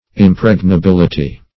Impregnability \Im*preg`na*bil"i*ty\, n.
impregnability.mp3